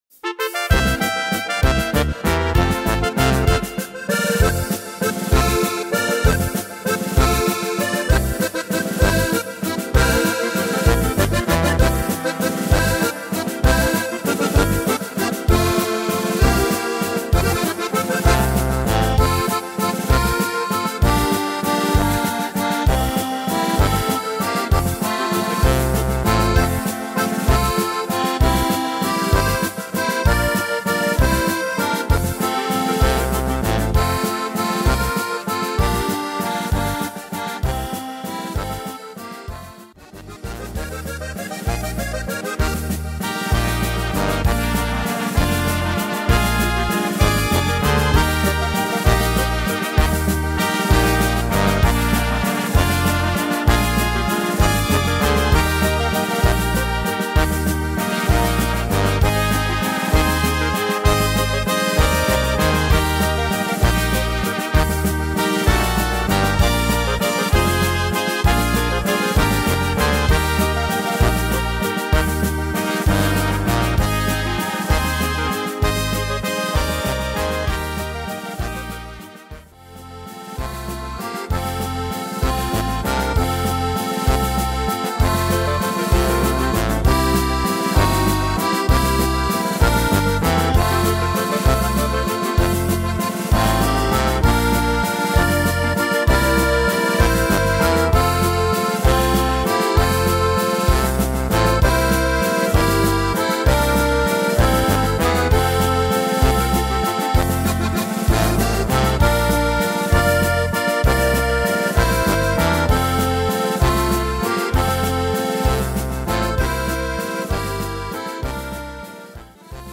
Tempo: 190 / Tonart: F-moll